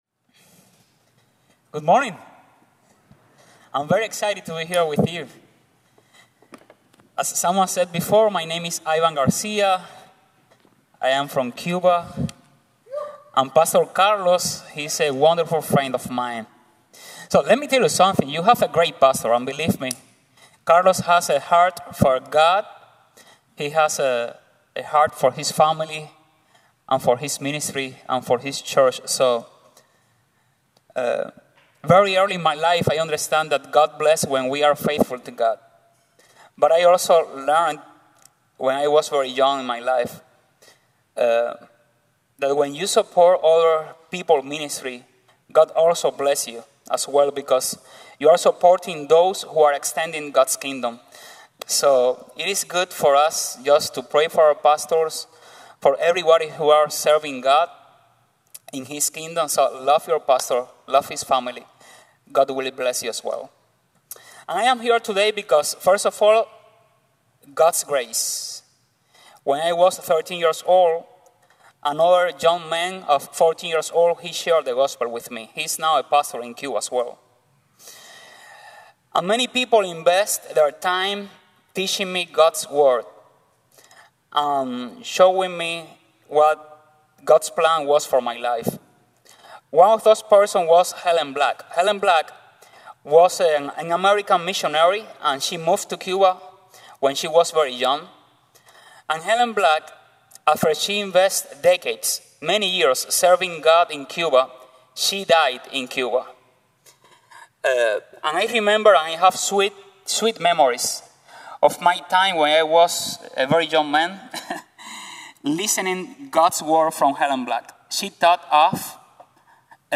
Anthropology | Sermon | Grace Bible Church